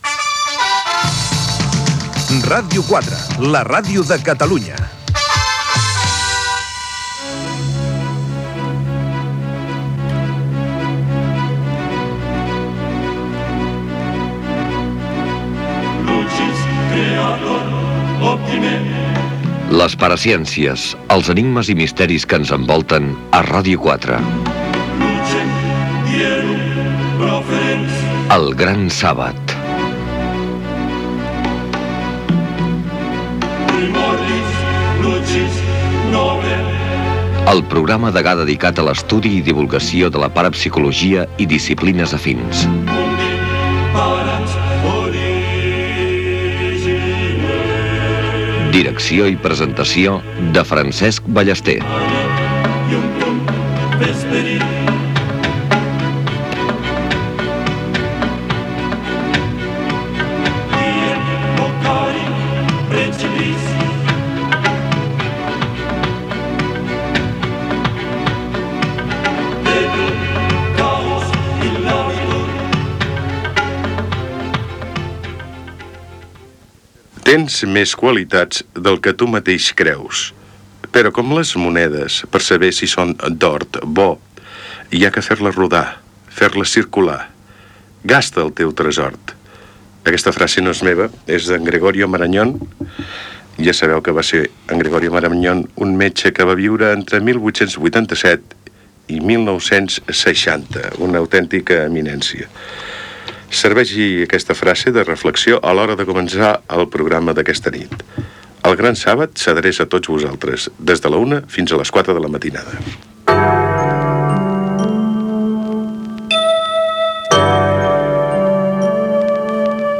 4b5588c03af5b62c9142fa3136a31682b5c7b55d.mp3 Títol Ràdio 4 - El gran sàbat Emissora Ràdio 4 Cadena RNE Titularitat Pública estatal Nom programa El gran sàbat Descripció Indicatiu de l'emissora i inici del programa.